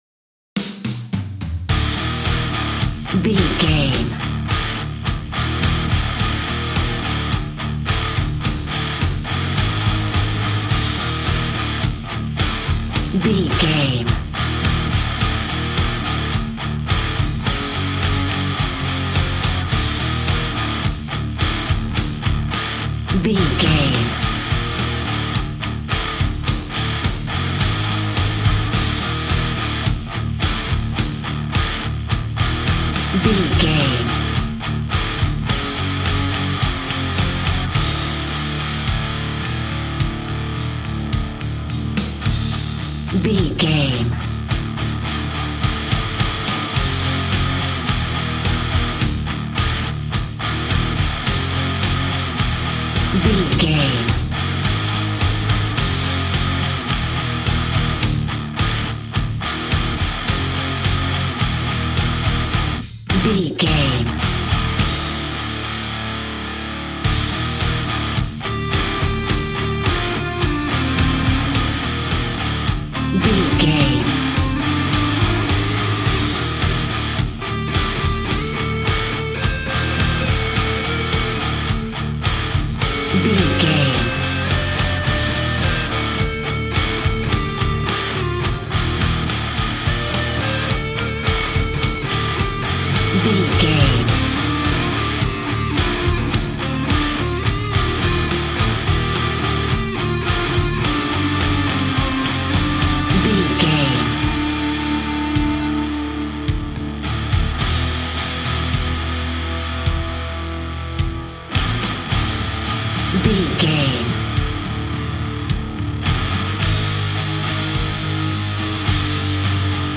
Epic / Action
Fast paced
Phrygian
hard rock
blues rock
distortion
rock instrumentals
Rock Bass
heavy drums
distorted guitars
hammond organ